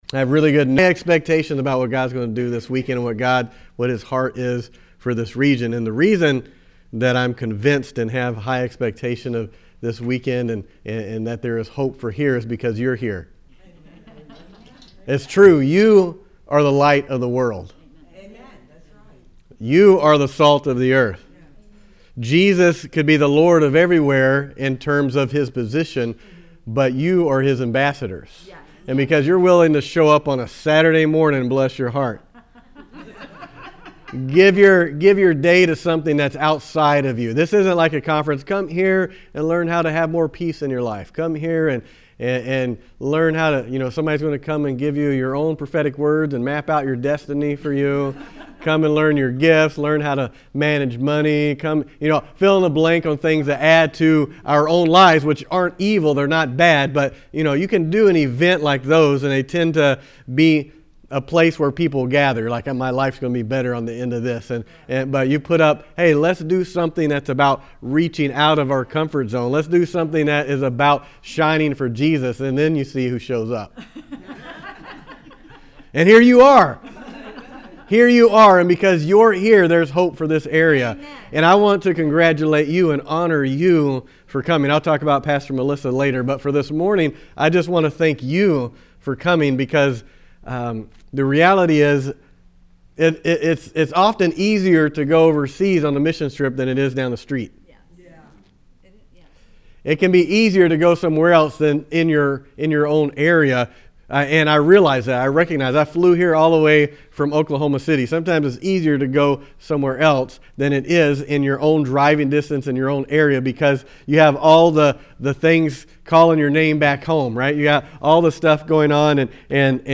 Guest Speaker
Saturday Morning Session